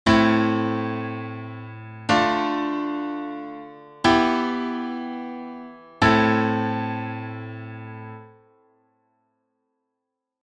For example, if we have a i-iv-v-i chord progression, it naturally resolves back to the tonic i chord:
i-iv-v-i Progression in A minor
This i-iv-v-i progression is based on the natural minor scale, as evidenced by the lack of raised sixth and seventh degrees.